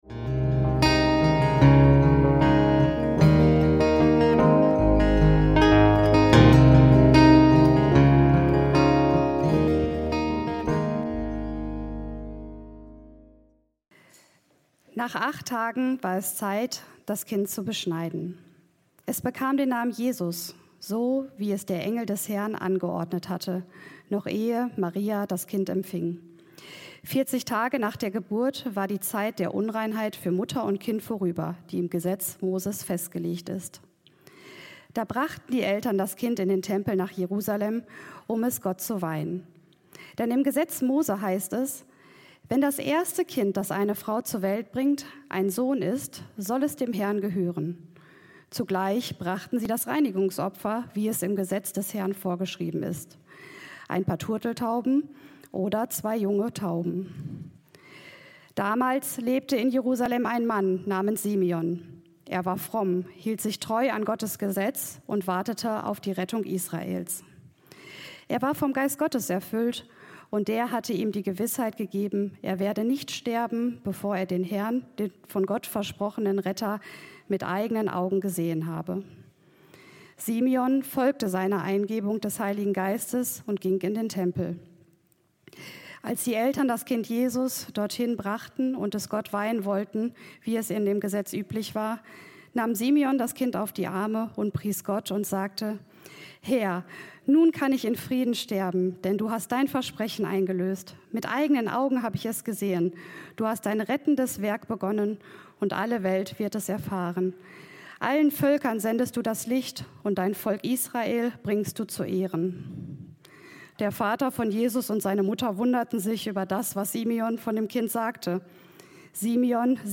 Gott kommt zu Simeon, Hanna und uns heute - Predigt vom 22.12.2024